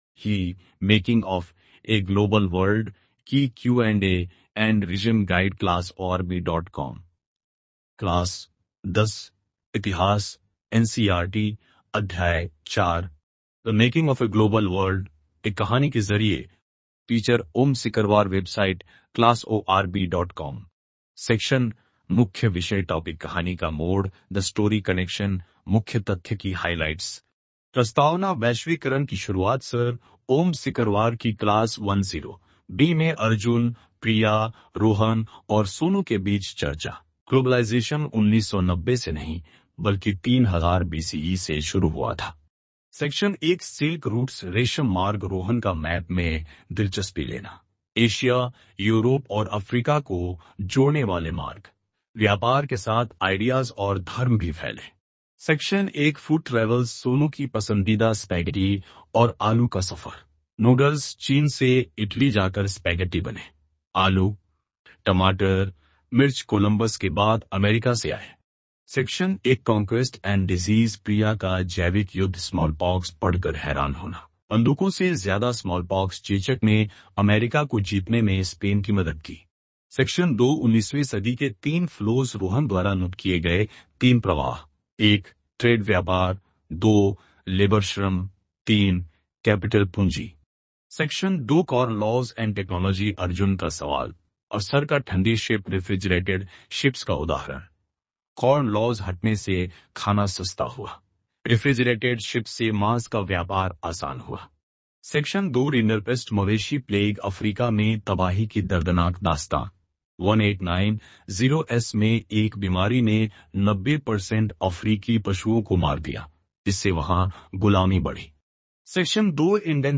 kabir_tts_audio-28-1.mp3